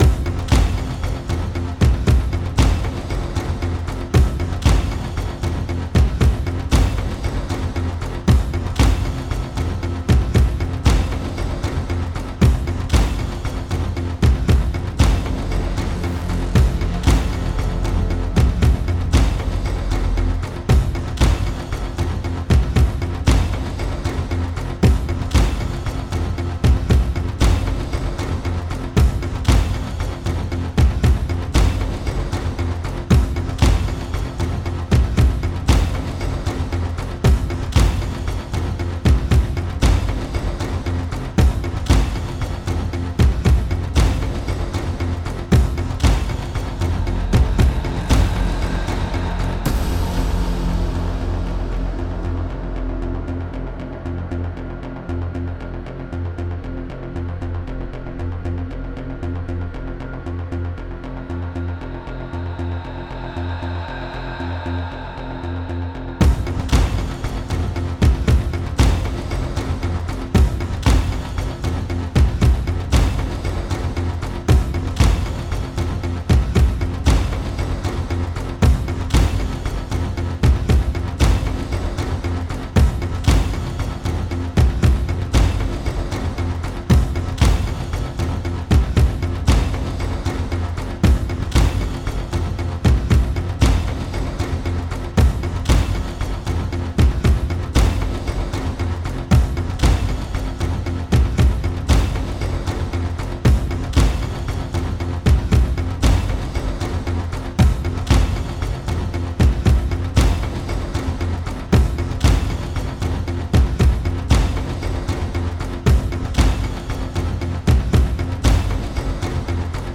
Add background music to Main Menu